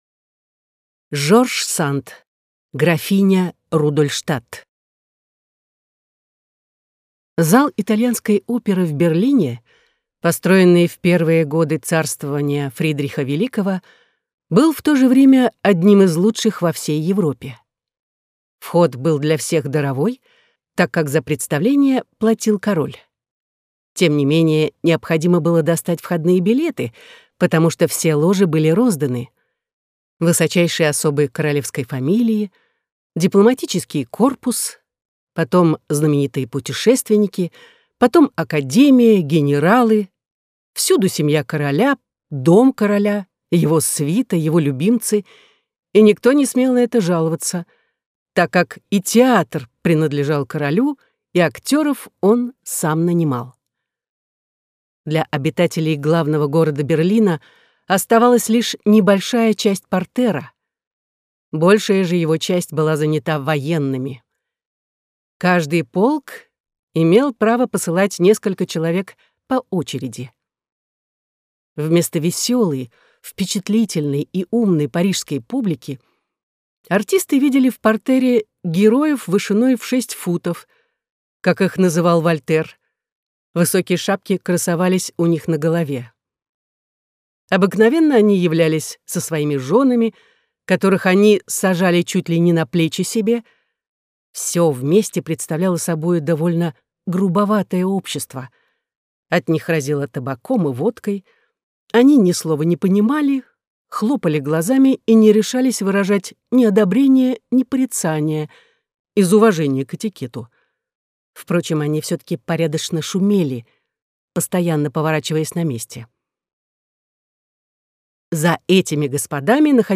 Аудиокнига Графиня Рудольштадт - купить, скачать и слушать онлайн | КнигоПоиск